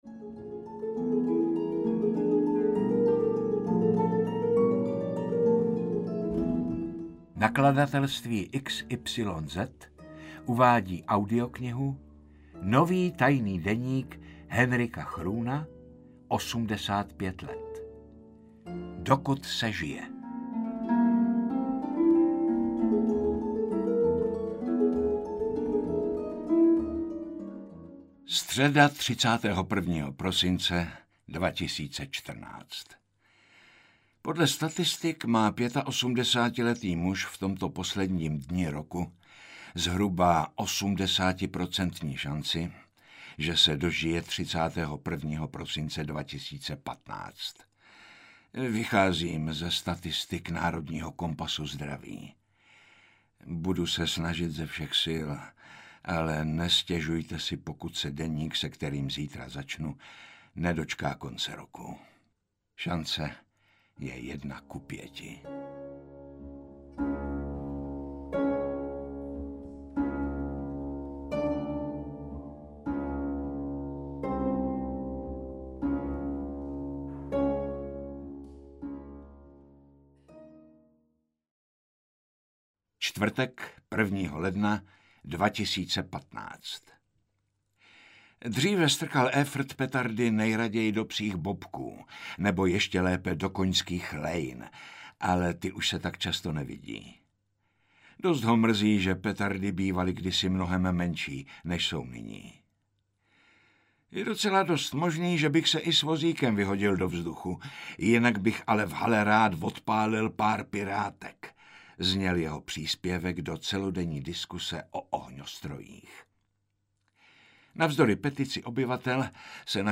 • AudioKniha ke stažení Nový tajný deník Hendrika Groena, 85 let
Interpret:  Jaromír Meduna